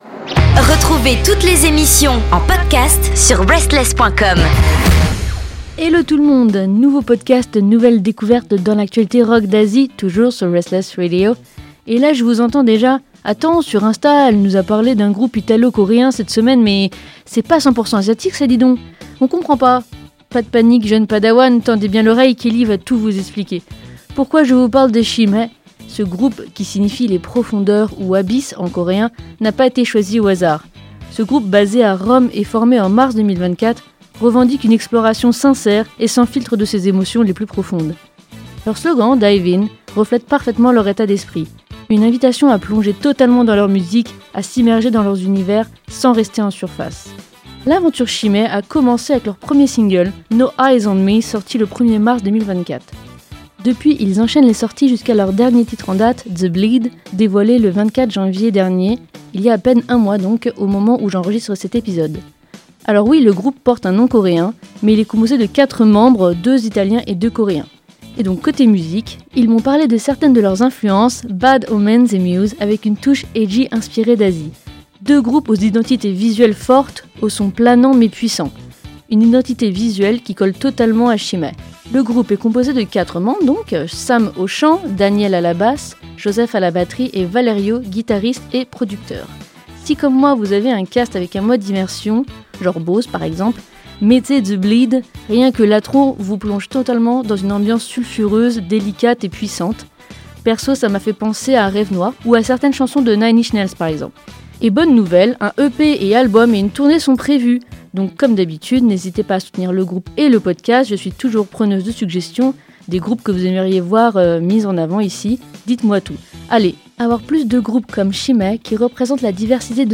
Leur univers sombre et sulfureux, mêlé à des paroles intenses et profondes, ne laisse personne indifférent.